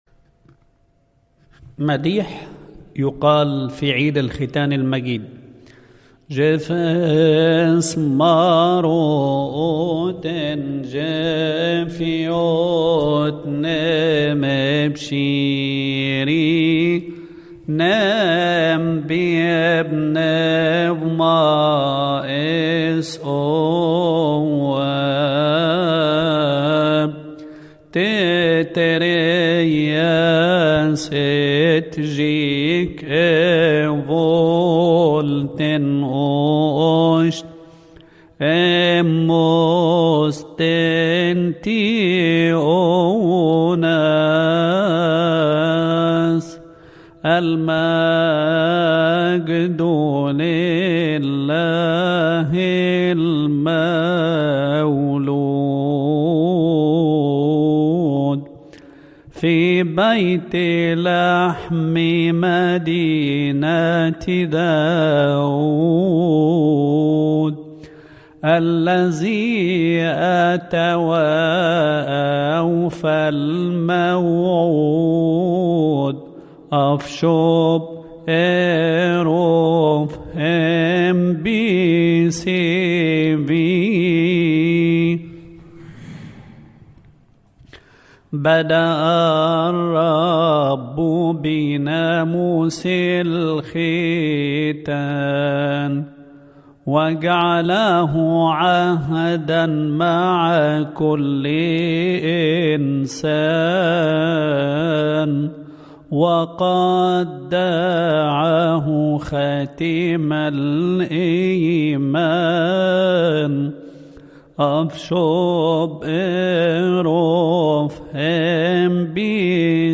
مديح توزيع عيد الختان - مكتبة الألحان - كنيسة الشهيد العظيم مارجرجس بنزلة السمان